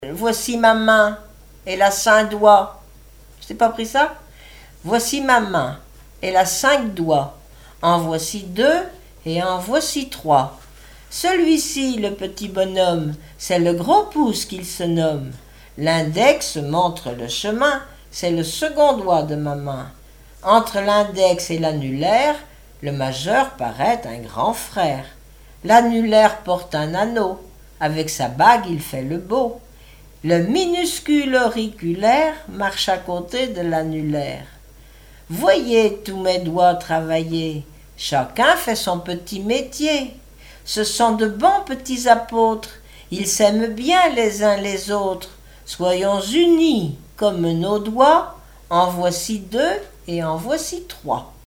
formulette enfantine : jeu des doigts
Témoignages et chansons
Pièce musicale inédite